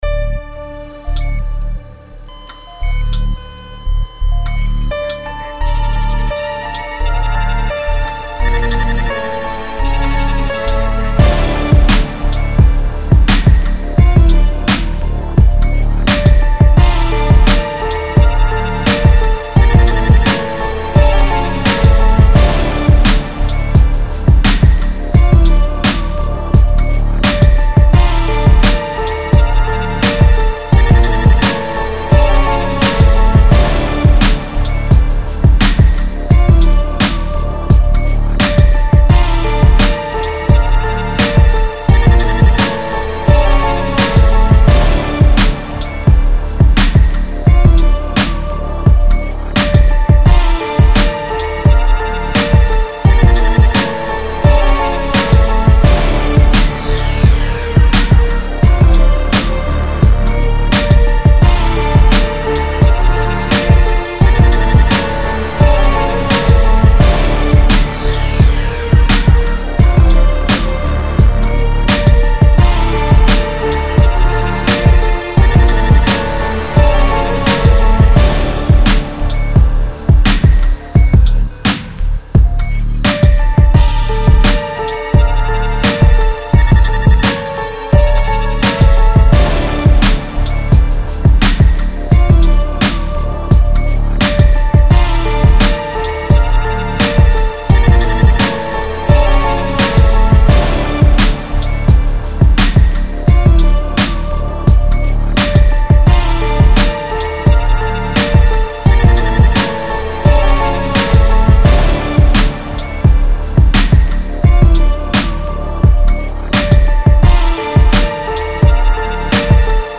Haunting melodies, grimy bass, rockish hip-hop type drums.
93 BPM.